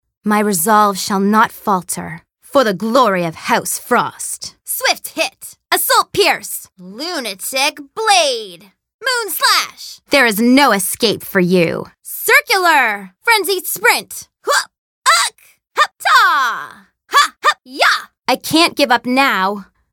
Female
I’m a Canadian voiceover artist with a native North American accent and a warm, youthful, relatable sound.
Microphone: Rode NT1-A